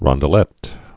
(rŏndl-ĕt, -dl-ā)